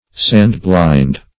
Sand-blind \Sand"-blind`\, a. [For sam blind half blind; AS.